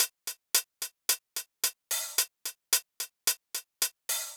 03 Hihat.wav